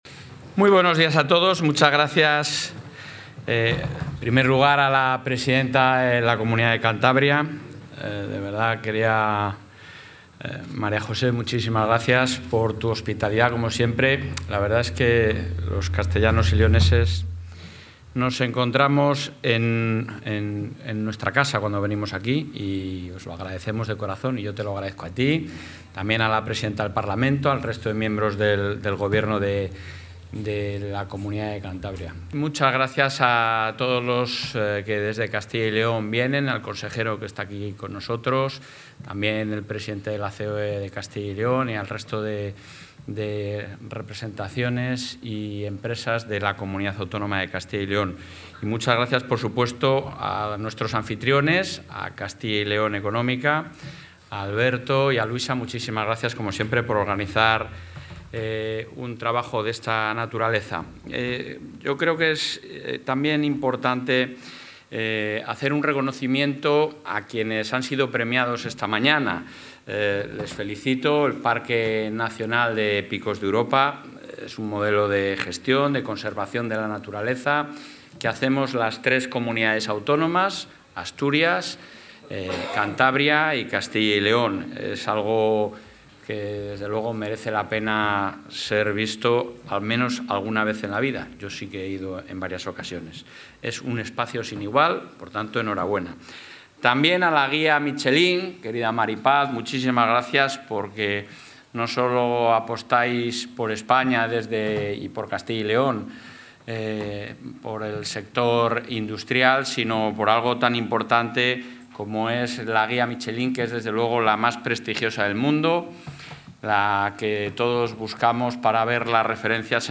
Intervención del presidente de la Junta.
El presidente del Ejecutivo autonómico, Alfonso Fernández Mañueco, ha participado hoy en Santander en la clausura del Foro3Mares, un espacio de encuentro entre el tejido empresarial de Castilla y León y Cantabria, organizado por la revista 'Castilla y León Económica'.